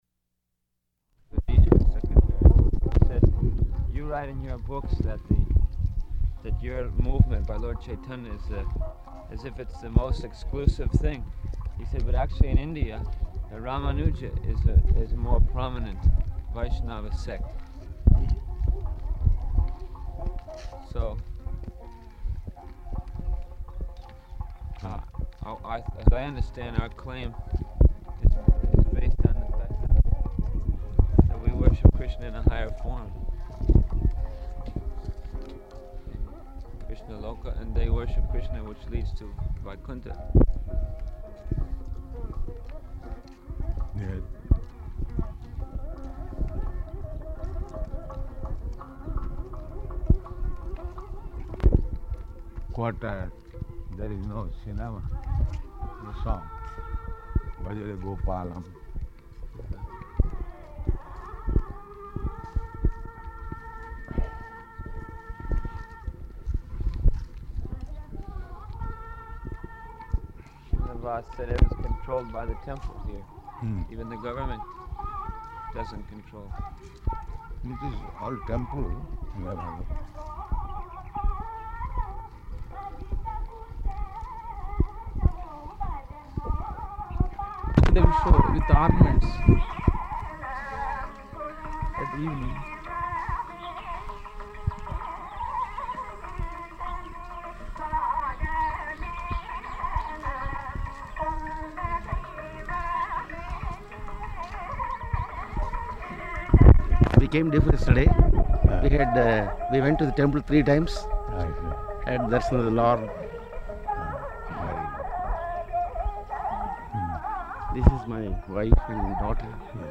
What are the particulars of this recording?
Location: Tirupati